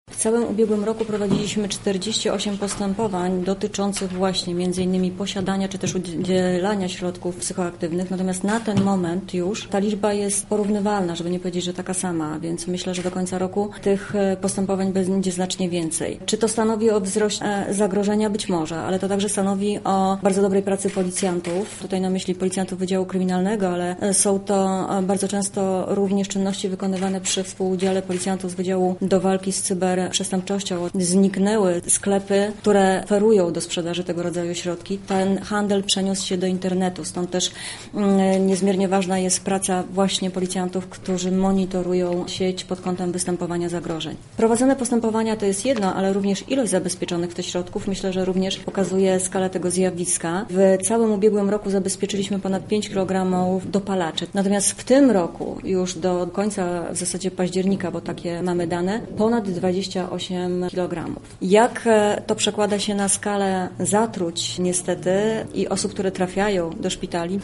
Na konferencji prasowej policja przedstawiła statystyki dotyczące walki z rozprowadzaniem nielegalnych substancji: